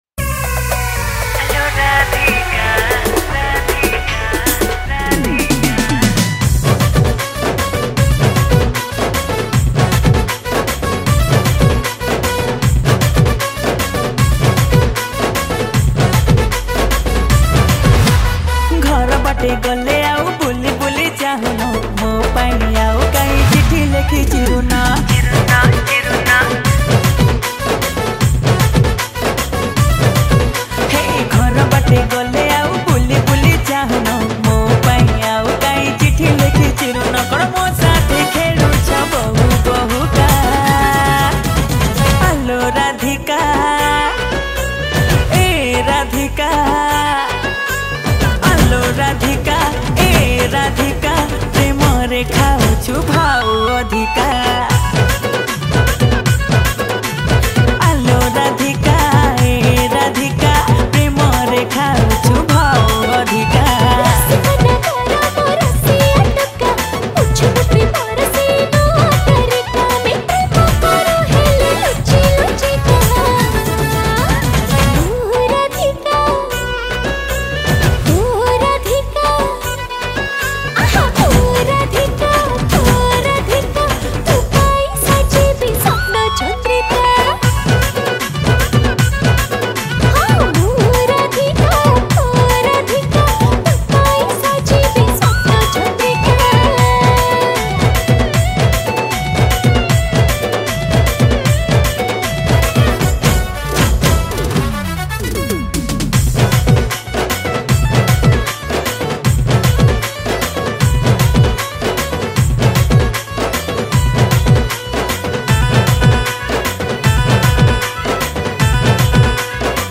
Dhol & Nisan